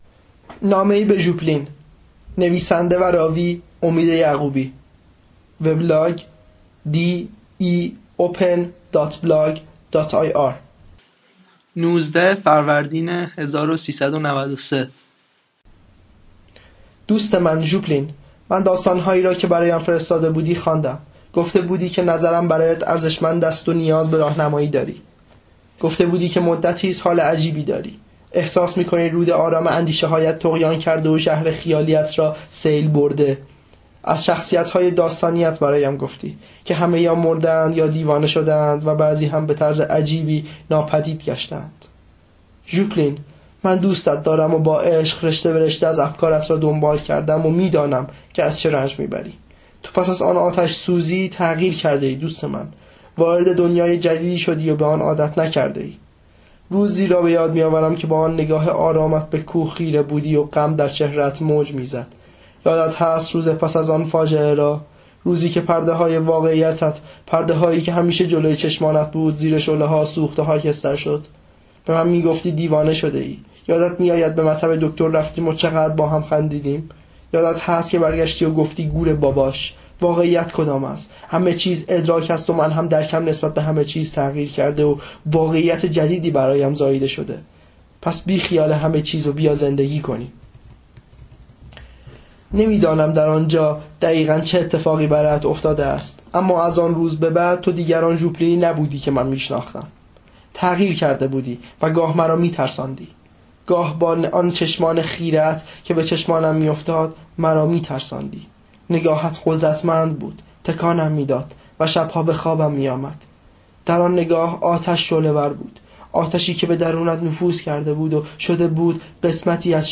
کتاب صوتی
داستان صوتی